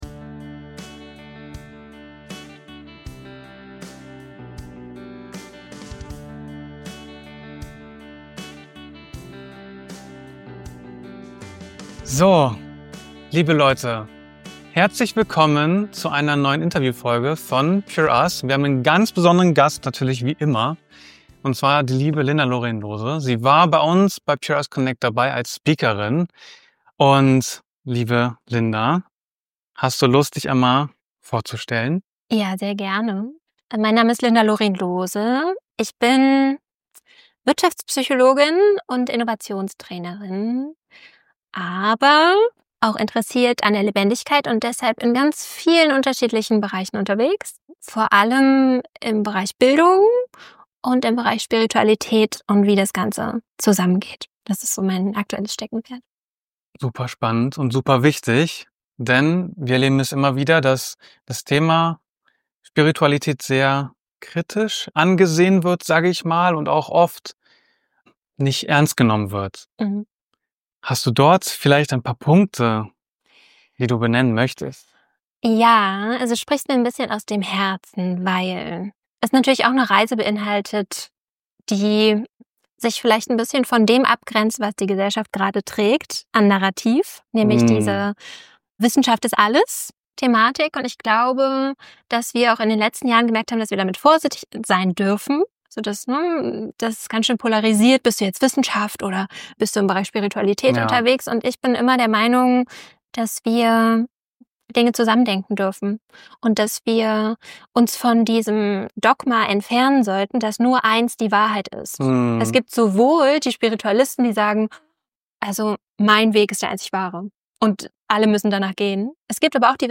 Wie Spiritualität dein Leben verändern kann – Interview